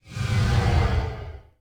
salvaging / DroneGo2.wav
DroneGo2.wav